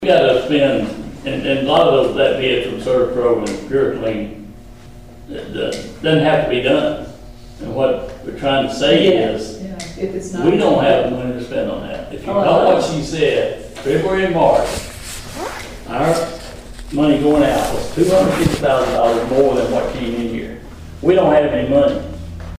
Cedar Bluff called a special meeting to discuss a recent fire inspection from the State Fire Marshal’s office.
The mold found in one of the buildings was then discussed as far as cost for testing and removal. Council member Jack Bond had this to say.